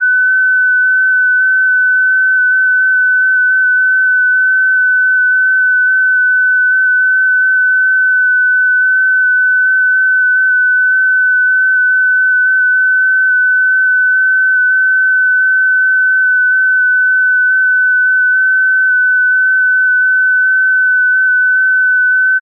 FST4, 30-sec mode
FST4-30.ogg